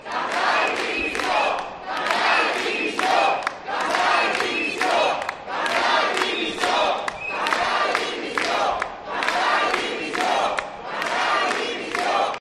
Una multitud de manifestants reclamen la dimissió del conseller d'educació